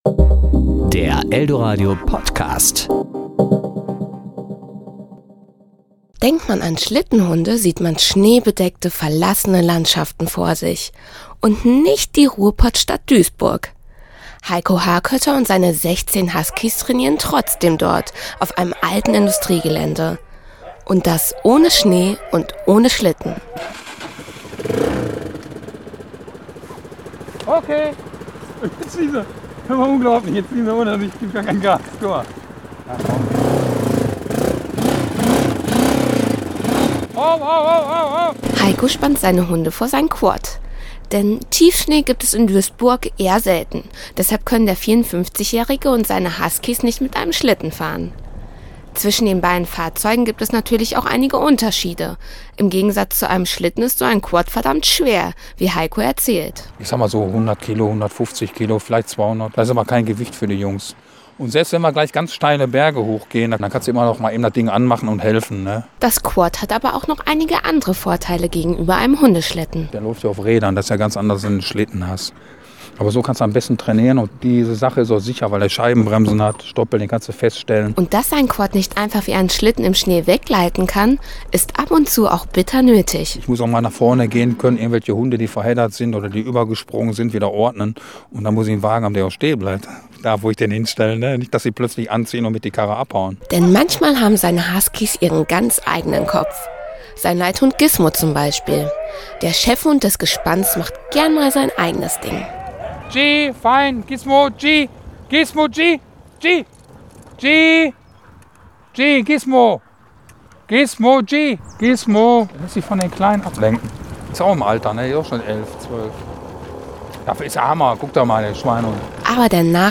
Serie: Beiträge  Ressort: Wort  Sendung: Toaster